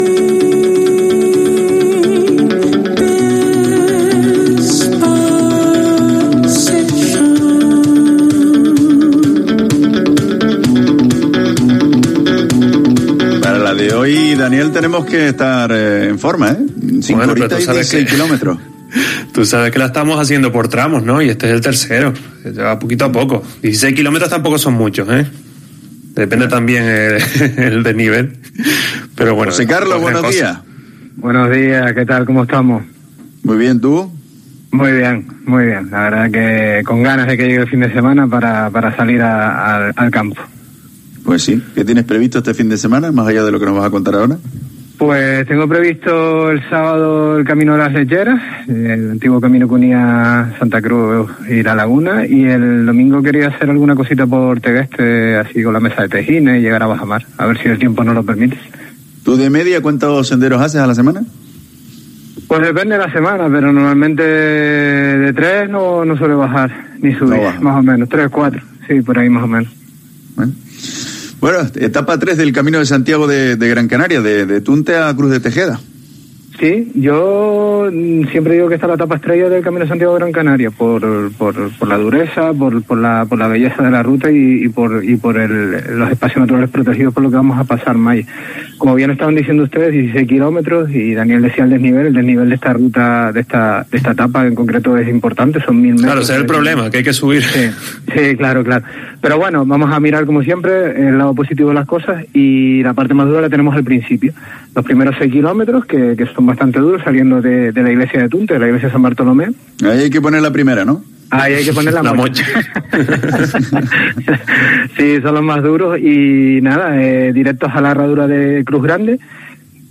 guía de montaña